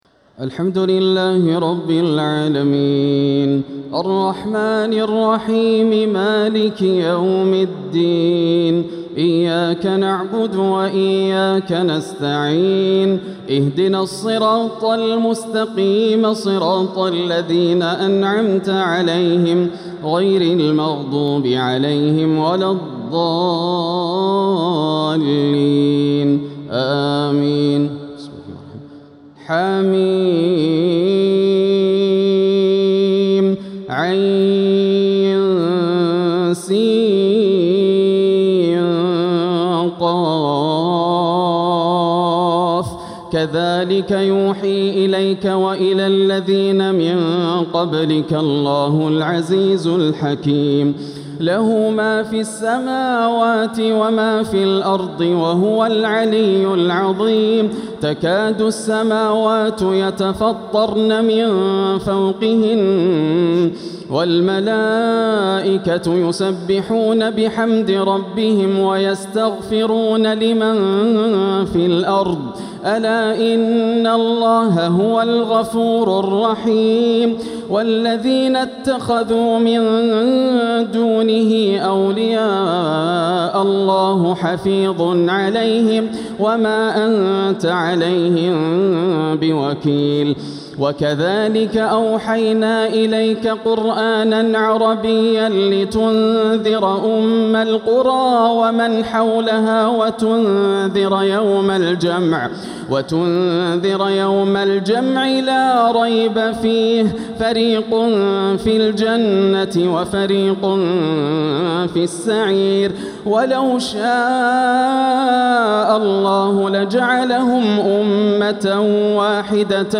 تراويح ليلة 26 رمضان 1447هـ سورة الشورى كاملة و الزخرف (1-45) > الليالي الكاملة > رمضان 1447 هـ > التراويح - تلاوات ياسر الدوسري